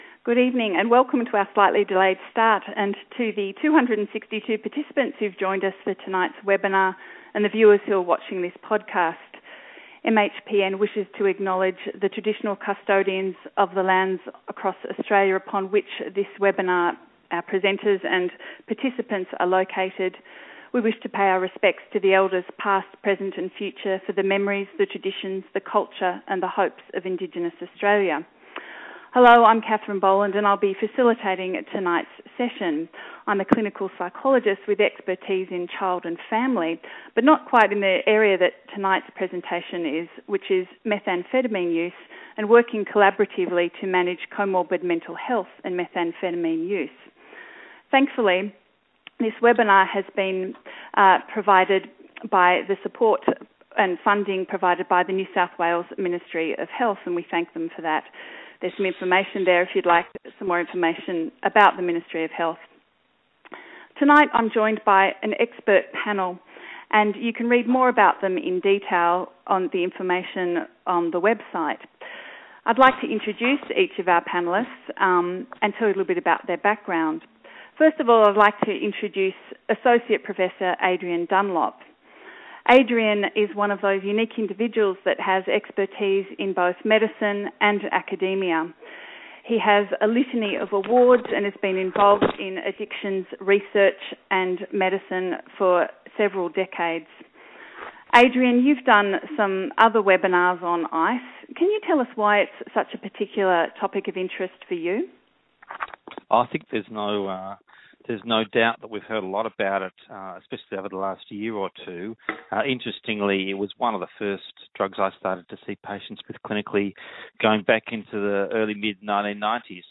Join our interdisciplinary panel of experts as we discuss comorbid mental health and methamphetamine use.